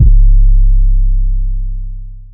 DEEDOTWILL 808 19.wav